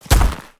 Sfx_creature_snowstalker_walk_07.ogg